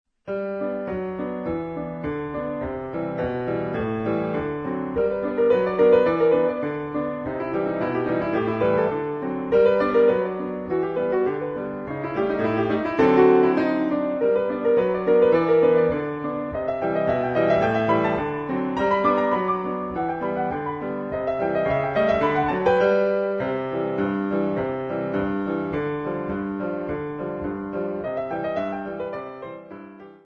12 Klavierstücke, mittelschwer
Besetzung: Klavier